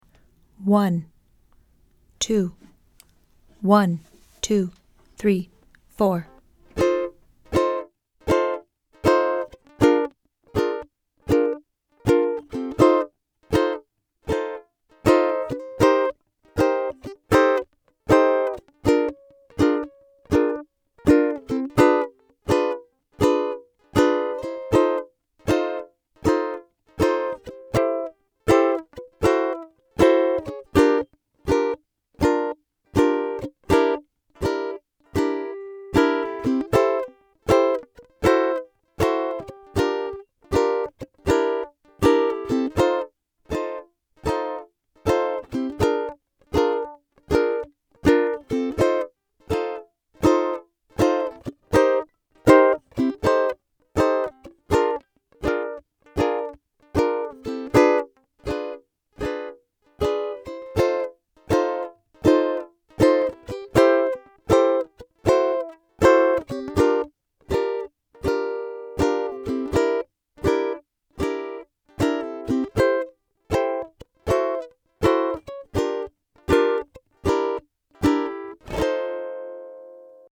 Backing Track: 12 Bar Blues - Variation 4 - Key of F